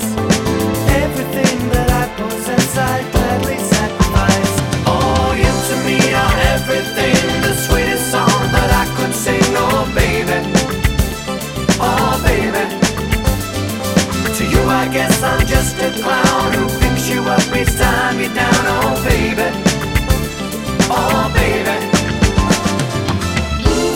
No Guitars Or Backing Vocals Disco 3:14 Buy £1.50